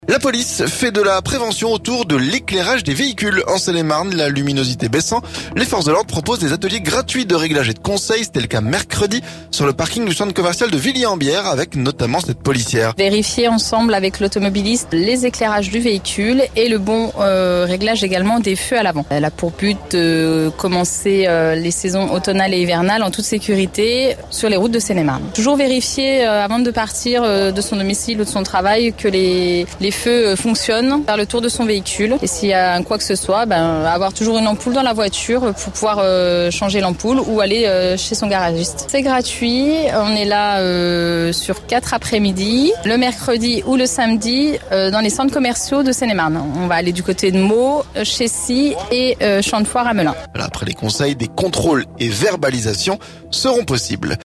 C'était le cas mercredi sur le parking du centre commercial de Villiers en Bière. Avec notamment cette policière.